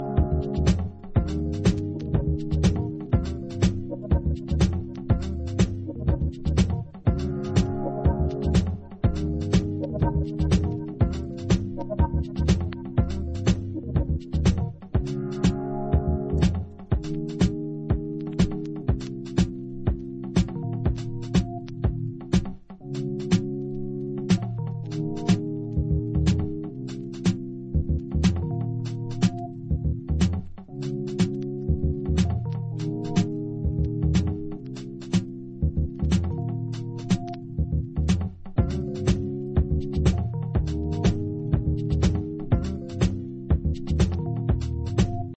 another atmospheric house influenced journey.
House